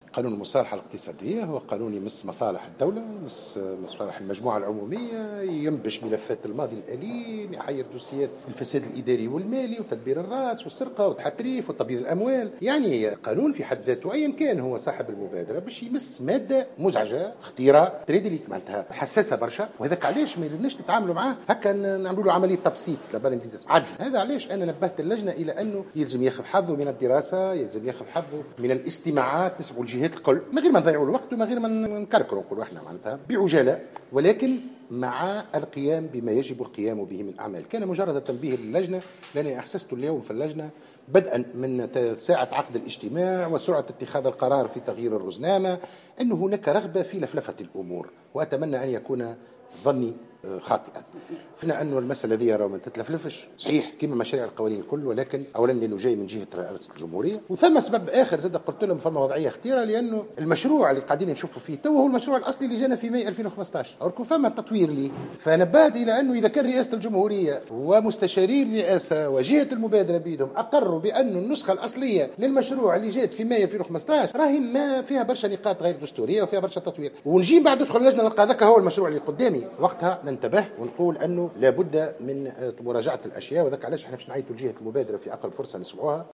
اعتبر النائب عن الجبهة الشعبية أحمد الصديق اليوم الثلاثاء أن هناك رغبة في "لفلفة" الأمور بشأن قانون المصالحة الذي اقترحته رئاسة الجمهورية، بحسب تعبيره في تصريحات صحفية على هامش اجتماع للجنة التشريع العام حول هذا المشروع.